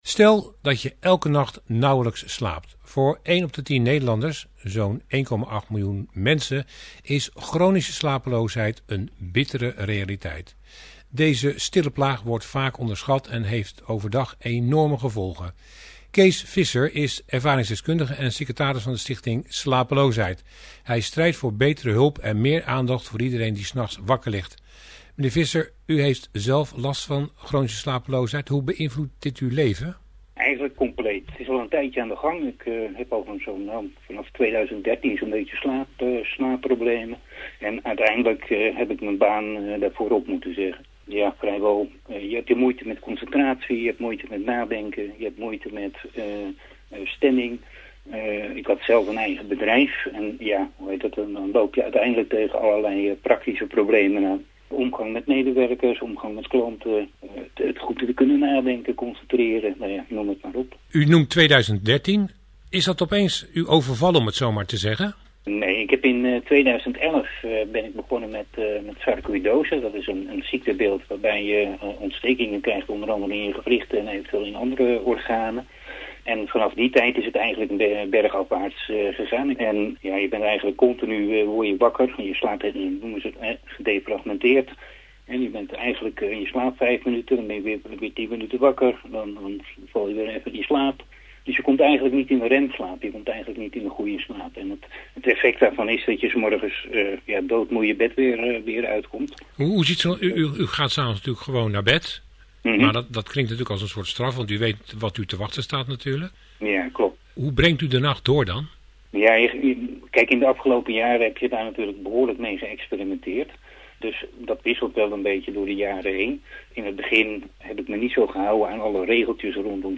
De interviews werden uitgezonden in: